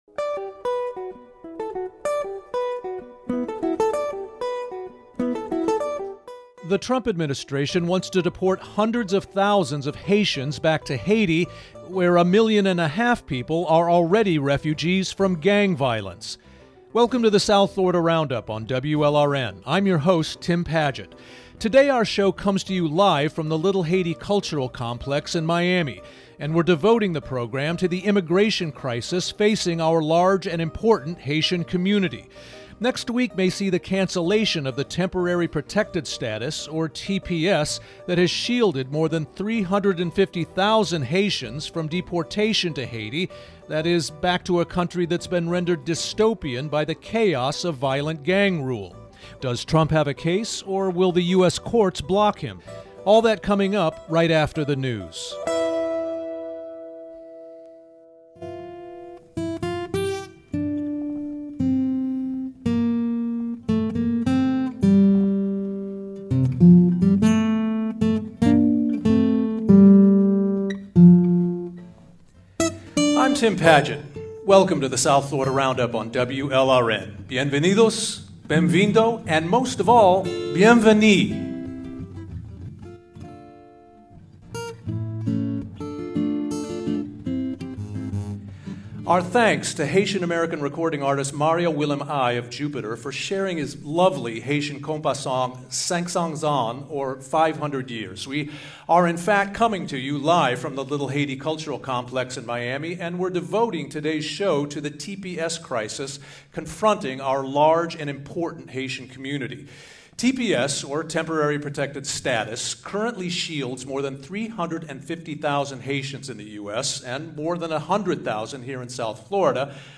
Live from Little Haiti: The uncertain future of TPS for Haitians
The South Florida Roundup Live from Little Haiti: The uncertain future of TPS for Haitians Play episode January 30 50 mins Bookmarks Episode Description On this episode of The South Florida, our show came to you live from the Little Haiti Cultural Complex in Miami, where we devoted the program to the immigration crisis facing our large and important Haitian community. Tuesday, Feb. 3, may see the cancellation of the Temporary Protected Status, or TPS, that has shielded more than 350,000 Haitians from deportation to Haiti — that is, back to a country that’s been rendered dystopian by the chaos of violent gang rule.